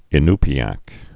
(ĭ-npē-ăk, -äk, -ny-)